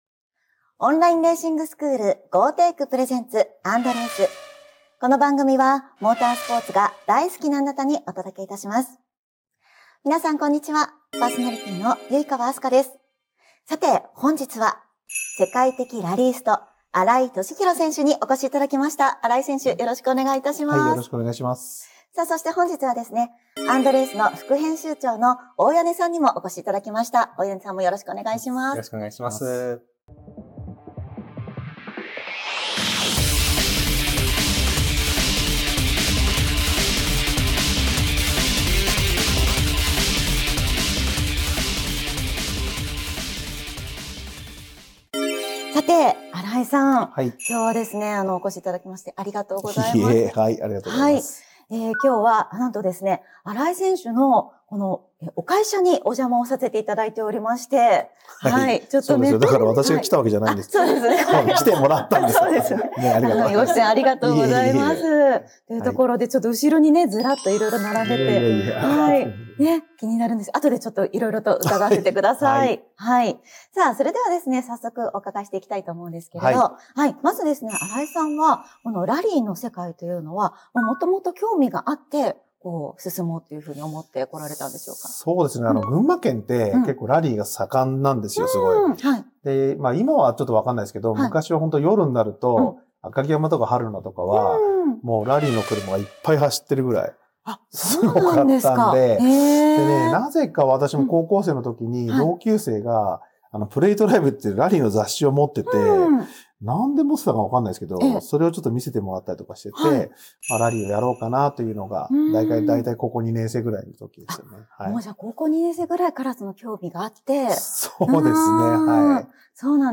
今回は、世界的ラリーストの新井敏弘選手をお迎えし、ラリーとの出会いや学生時代の奮闘を語っていただきました。群馬の峠や林道で鍛えたAE86での練習、ラリー雑誌との運命的な出会い、バイト代がすべてガソリンと修理費に消えた日々…。知られざる“群馬ラリー文化”の中で育まれた実力と、アツい原点ストーリーが詰まっています。